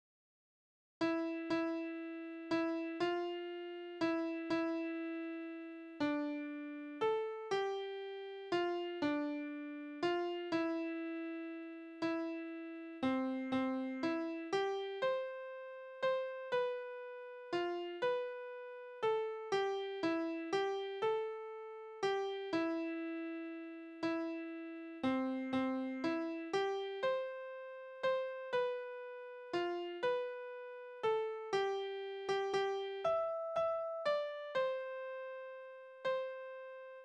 Kinderspiele: Auszählen
Tonart: C-Dur
Taktart: 3/4
Tonumfang: große Dezime
Besetzung: vokal
Anmerkung: Vortragsbezeichnung: Walzerzeitmaß